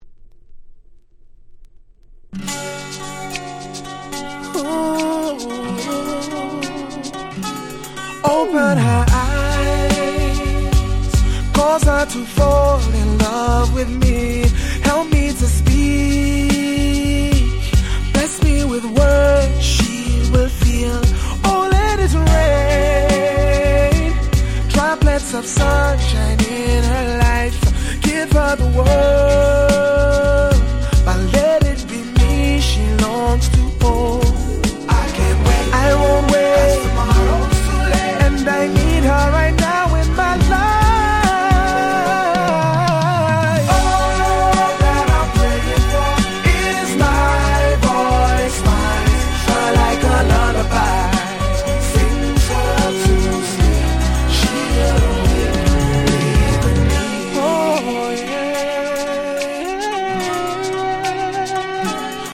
03' Very Nice UK R&B EP !!